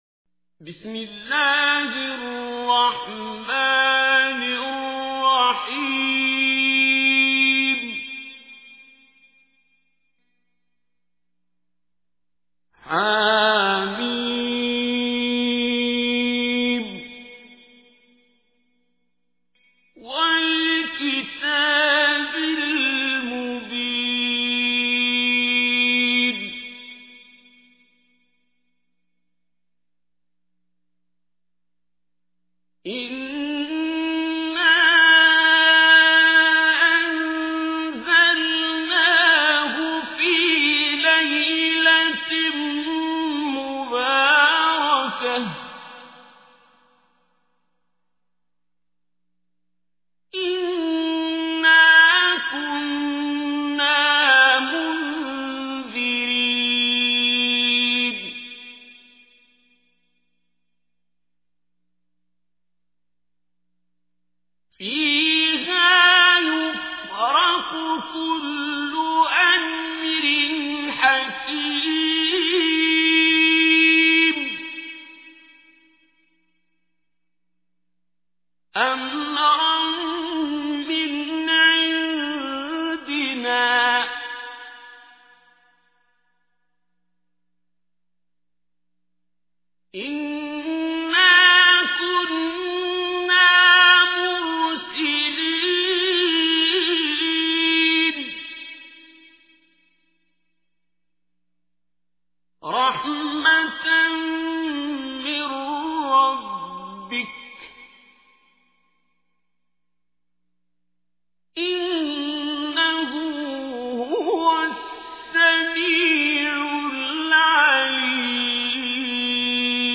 تحميل : 44. سورة الدخان / القارئ عبد الباسط عبد الصمد / القرآن الكريم / موقع يا حسين